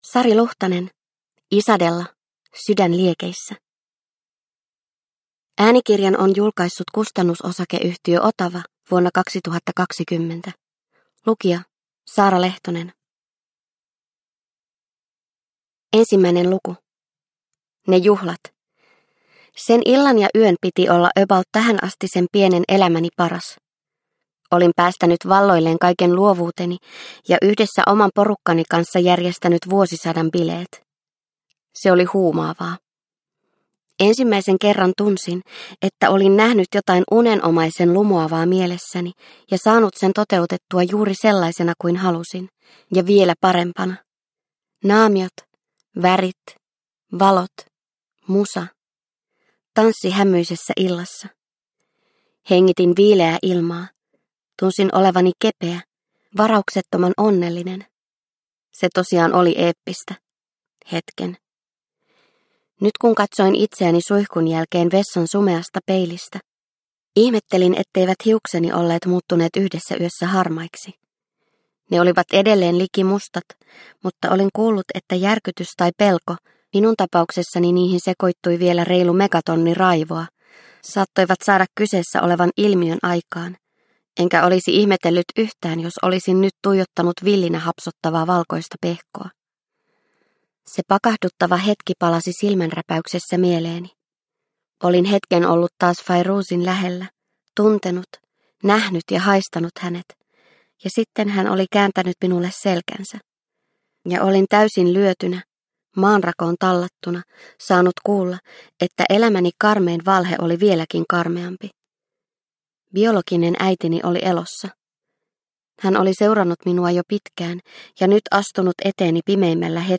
Isadella - Sydän liekeissä – Ljudbok – Laddas ner